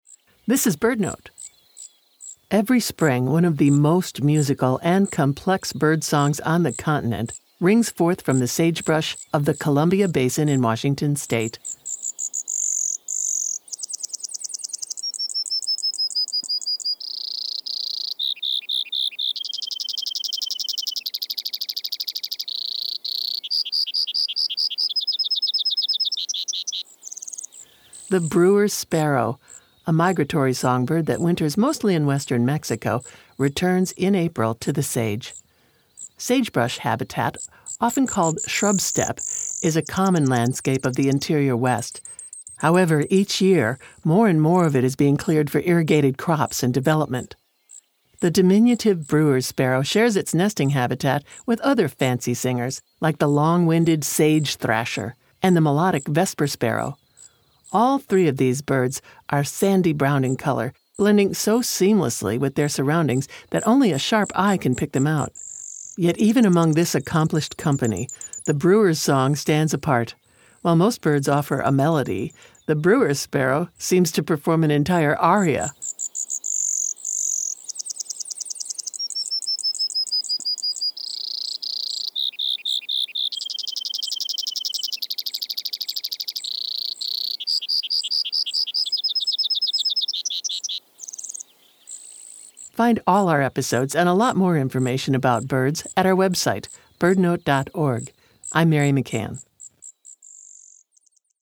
One of the most musical and complex bird songs in the US is that of the Brewer’s Sparrow. It’s a veritable aria, ringing forth from the sagebrush of Eastern Washington’s Columbia Basin. Shrub-steppe is disappearing from the interior west as it is cleared for irrigated crops.